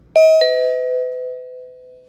Sound Effects
Upanup Doorbell